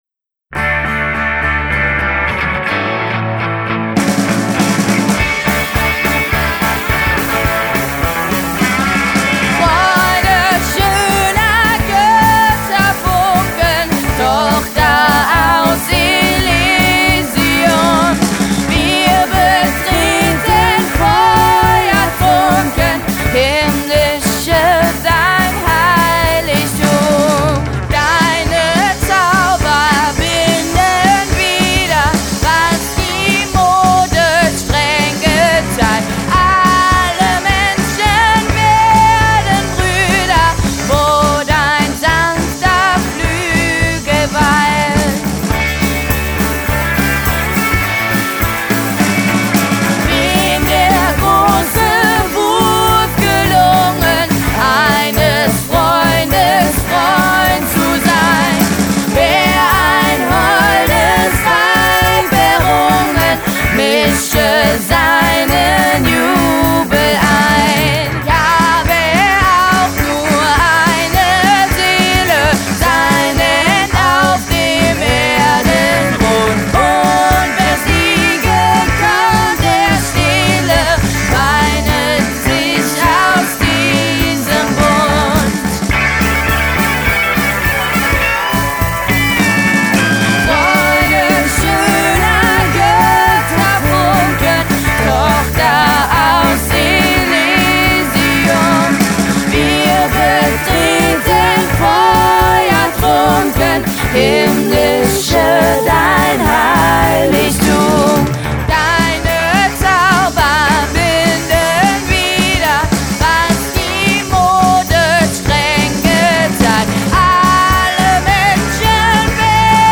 Vertonung: ClassicRocks Musikernetzwerk
singen und spielen die bekannte Europahymne hier auf ihre ganz eigene Weise
Drums